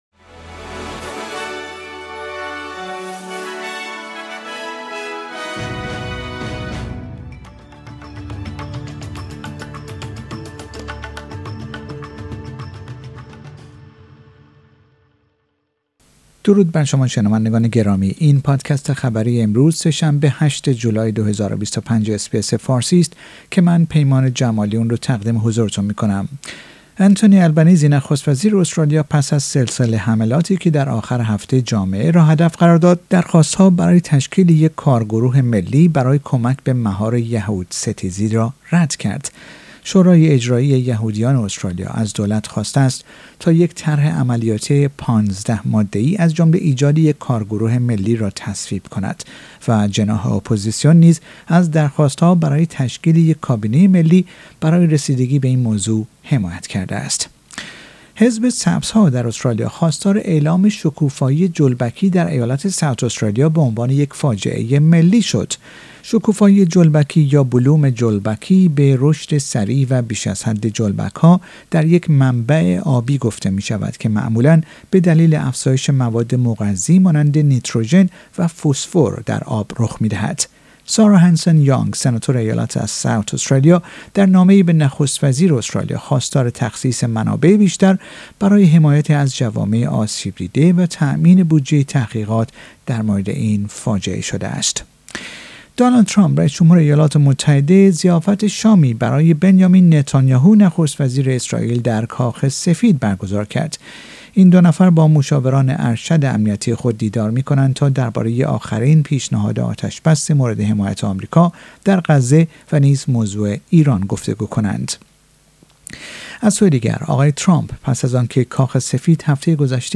در این پادکست خبری مهمترین اخبار امروز سه شنبه هشتم جولای ارائه شده است.